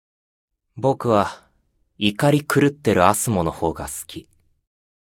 Character Voice Files > Belphegor Voice Files